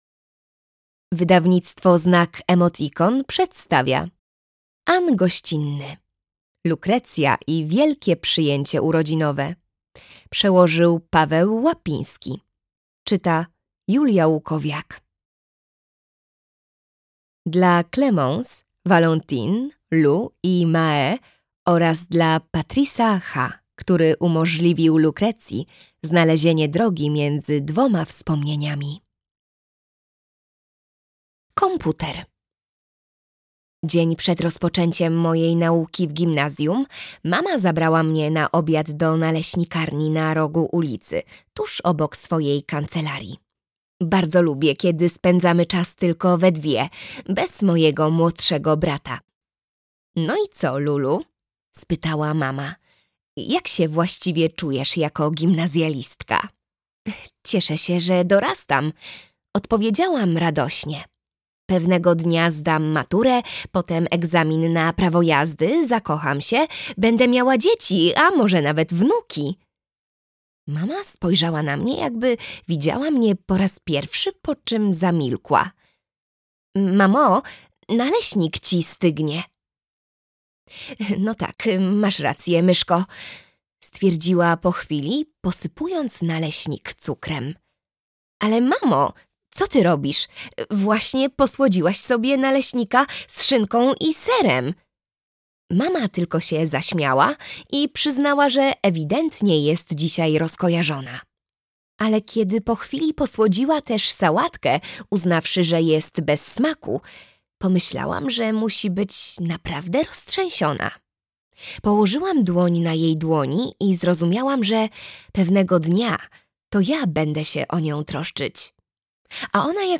Lukrecja i wielkie przyjęcie urodzinowe - Anne Goscinny - audiobook + książka